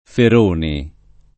[ fer 1 ni ]